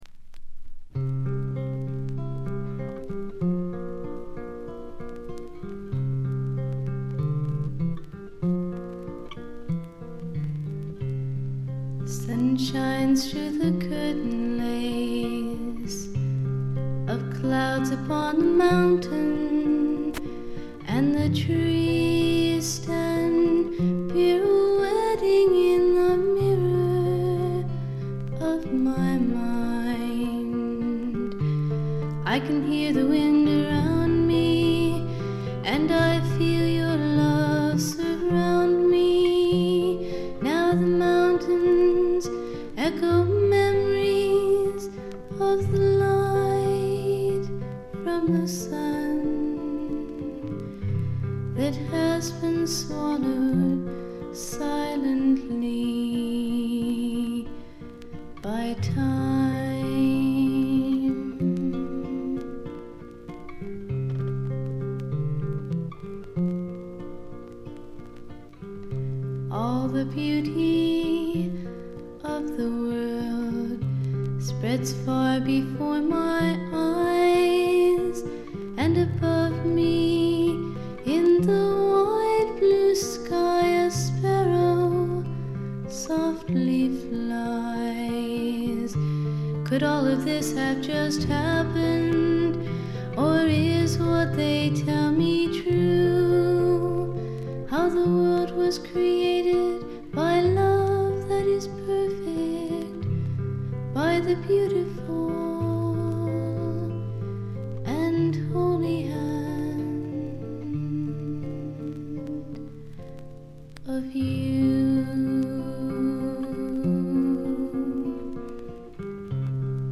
静音部でバックグラウンドノイズ。
演奏はほとんどがギターの弾き語りです。
この純真なドリーミー感覚はめったに得られない貴重なもの。
試聴曲は現品からの取り込み音源です。